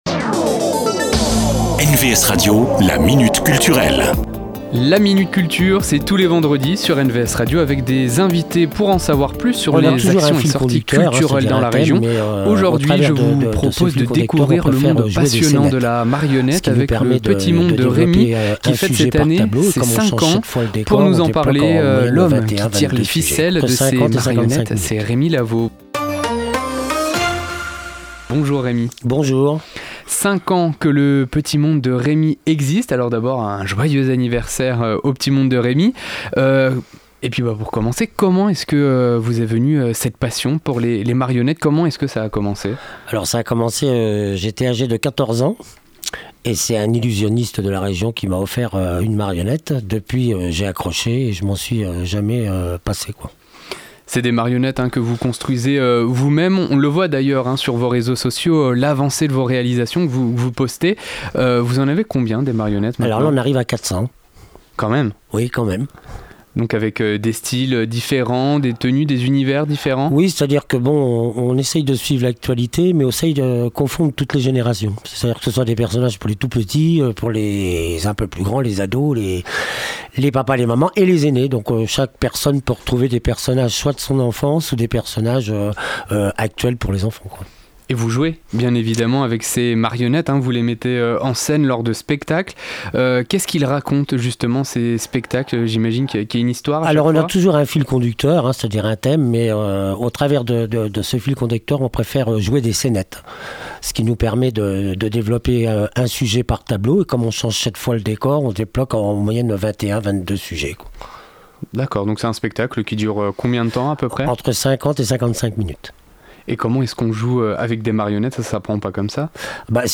La Minute Culture, rencontre avec les acteurs culturels de la région.